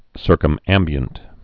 (sûrkəm-ămbē-ənt)